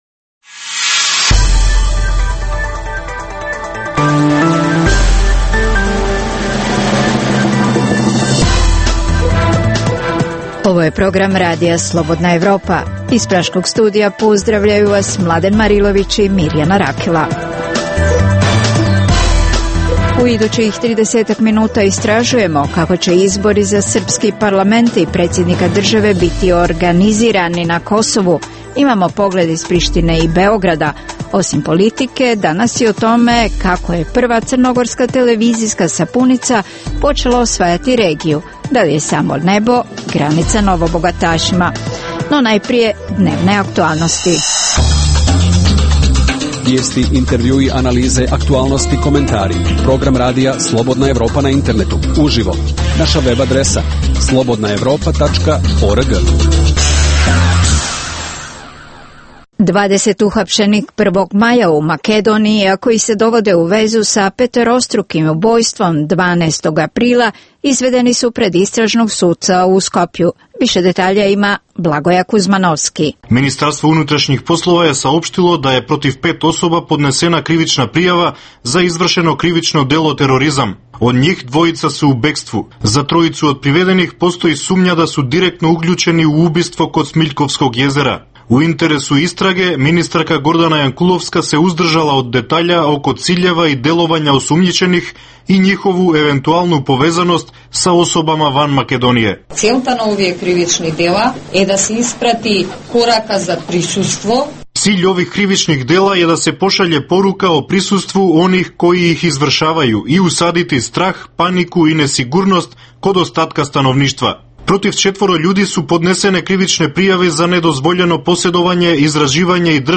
U emisiji možete čuti: - Kako će biti organizirani izbori za srpski parlament i predsjednika države na Kosovu pojašnjavaju reporteri iz Prištine i iz Beograda. - Romi ponovno meta napada u Srbiji.